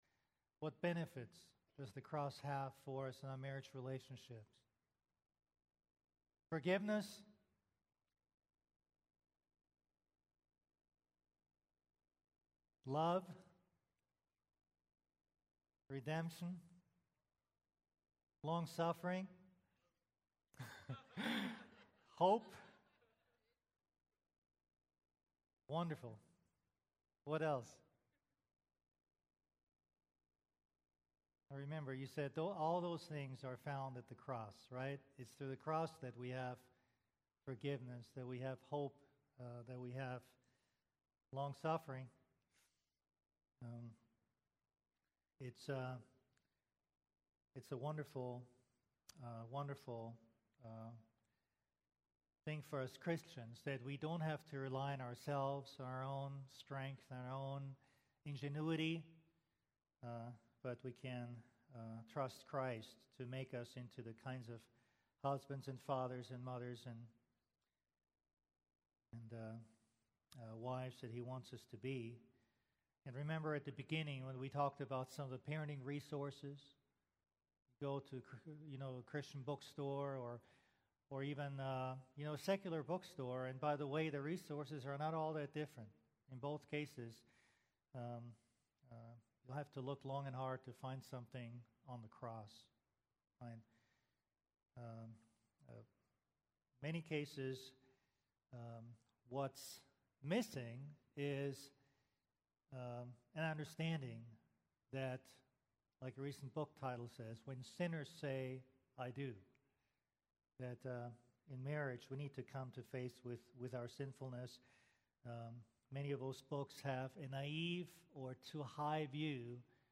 Parenting Conference RCCC General Session 1 Part 2.mp3